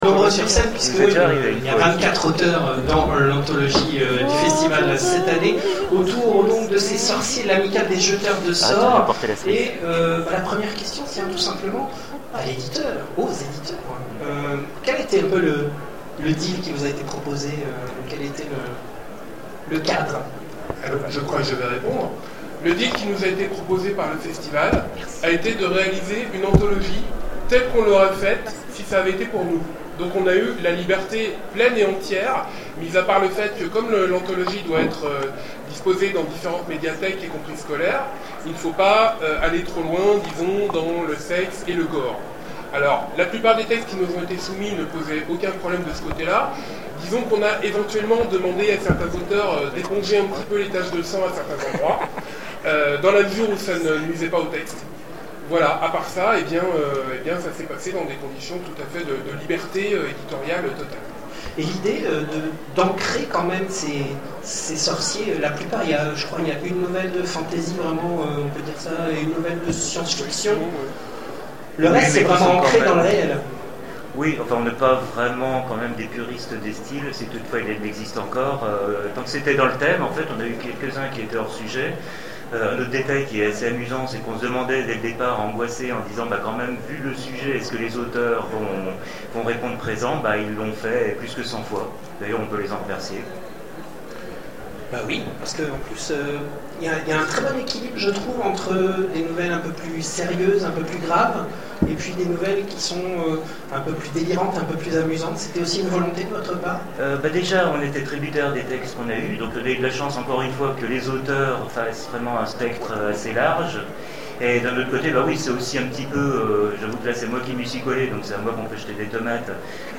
Zone Franche 2013 : Conférence L'Amicale des jeteurs de sorts